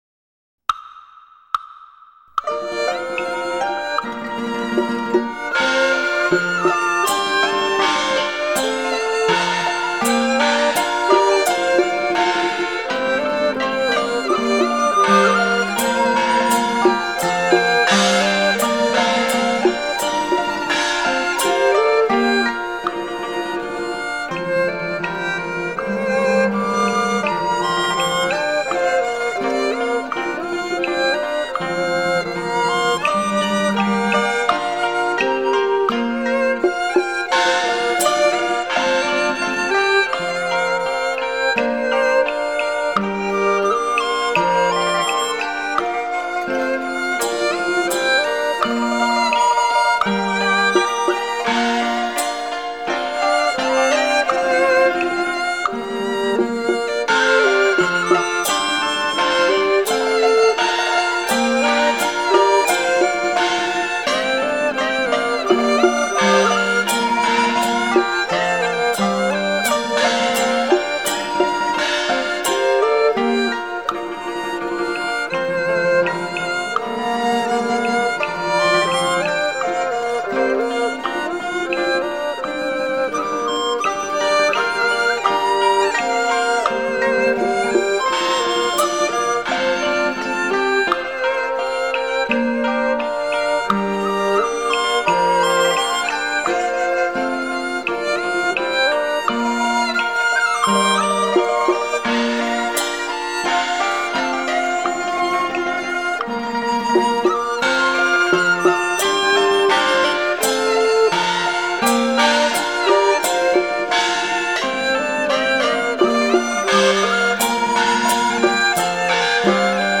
CD1：傳統客家歌謠 CD2：傳統客家歌謠
伴唱><演唱>